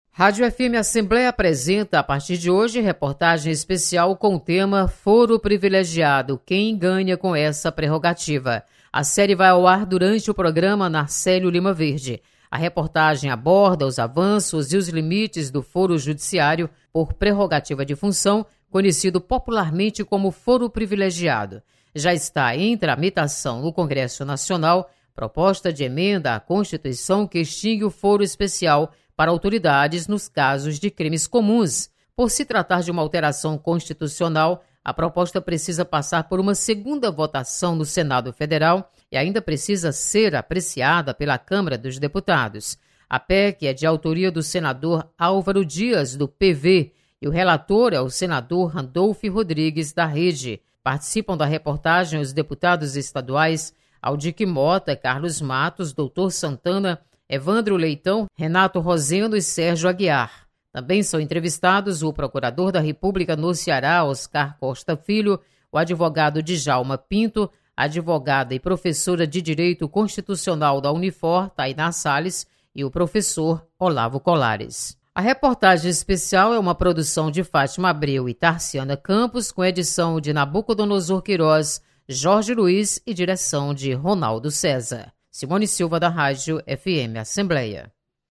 Foro privilegiado é tema de reportagem especial.